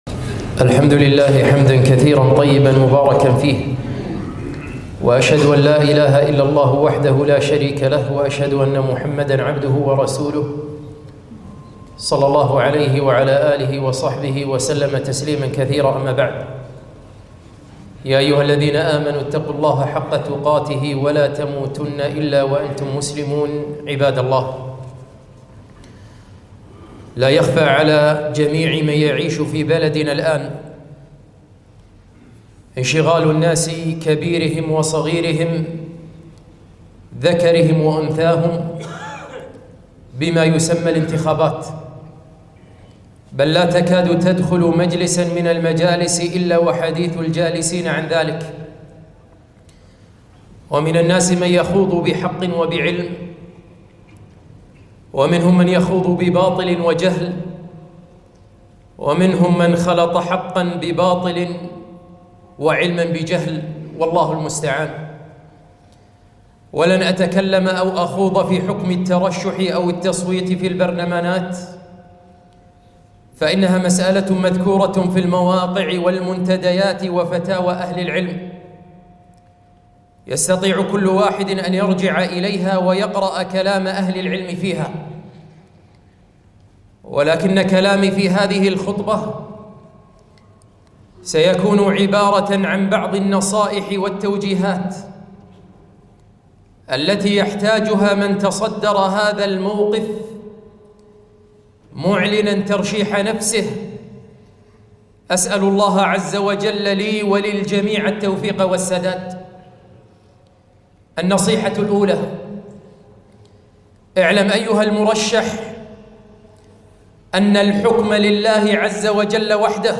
خطبة - نصائح وتوجيهات لمن ترشح للانتخابات